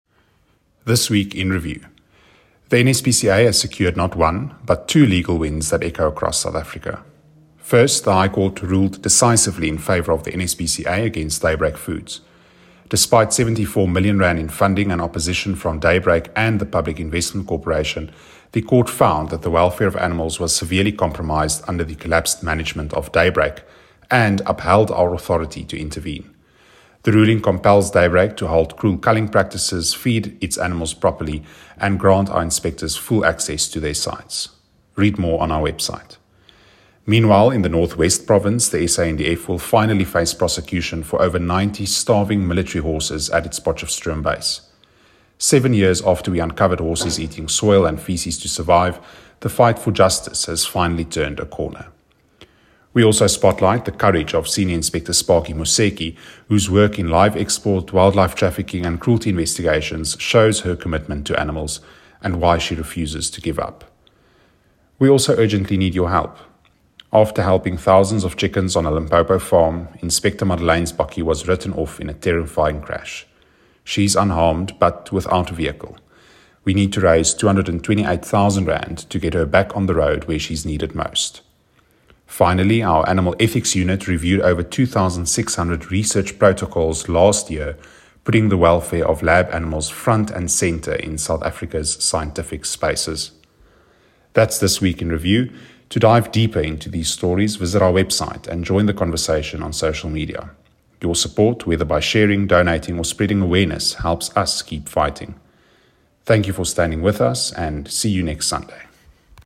WiR-10-Voice-Over-MP3.mp3